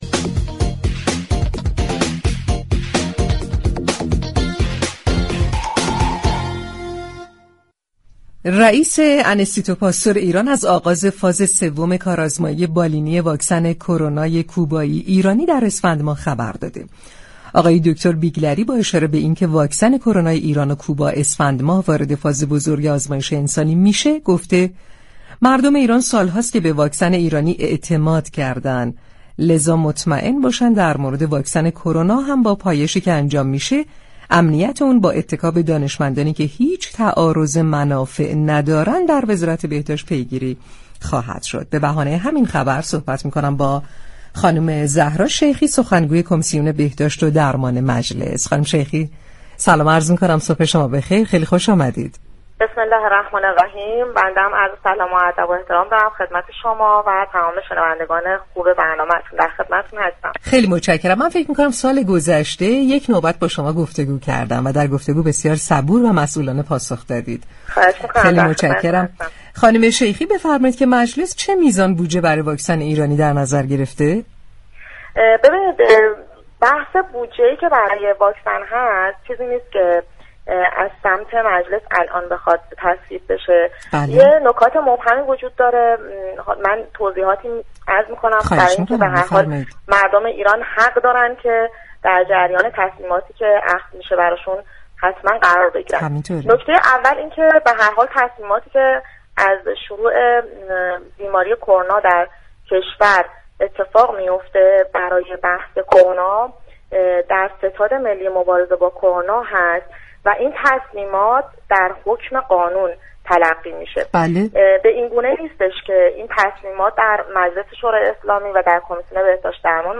به گزارش پایگاه اطلاع رسانی رادیو تهران، زهرا شیخی در گفتگو با برنامه تهران ما سلامت درباره بودجه تخصیص یافته به واكسن كرونا گفت: تمامی تصمیمات مربوط به كرونا در ستاد مقابله با كرونا اتخاذ می شود و در حكم قانون است و به این صورت نیست كه این قوانین در مجلس به تصویب برسند.